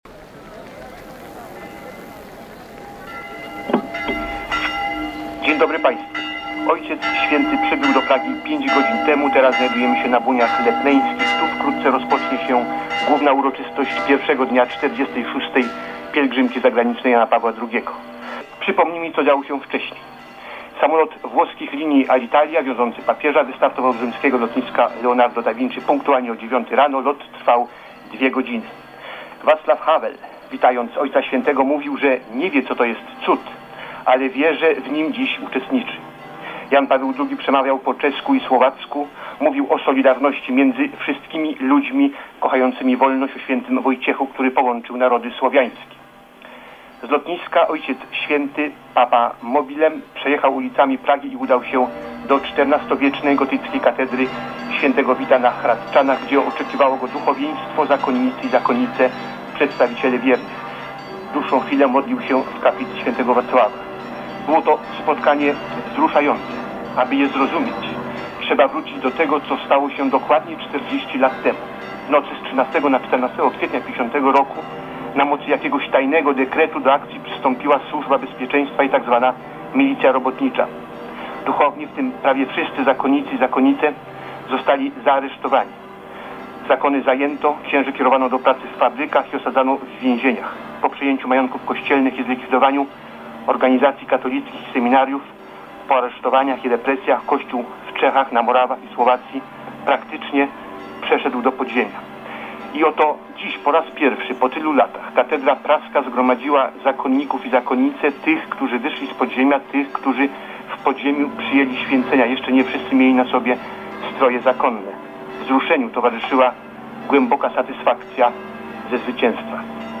Czechosłowacja 1990 r. - fragment audycji PR